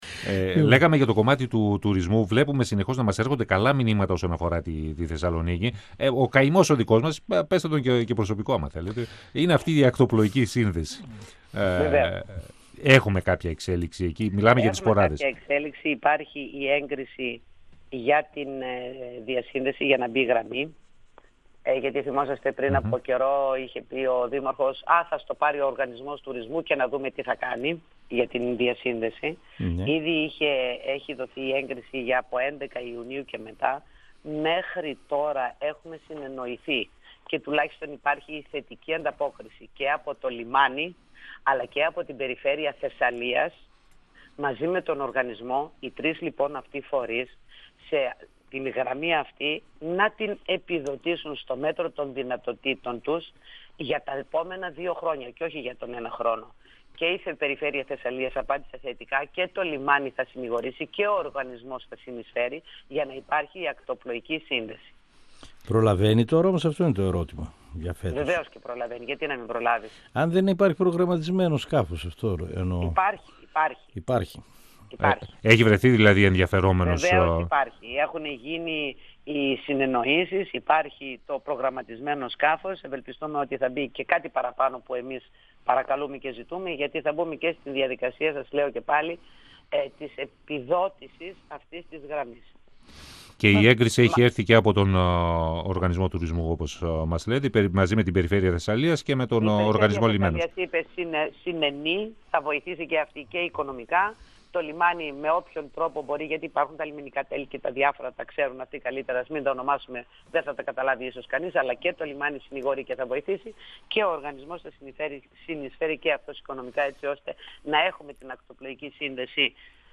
Η αντιπεριφερειάρχης και πρόεδρος του Οργανισμού Τουρισμού Θεσσαλονίκης, Βούλα Πατουλίδου, στον 102FM του Ρ.Σ.Μ. της ΕΡΤ3
Συνέντευξη